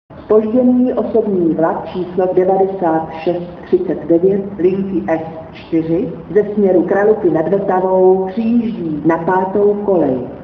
Přidávám zase nějaké to hlášení..
Os 9639 S4 - přijíždí (Praha-Masarykovo nádraží).wma (178.5 k)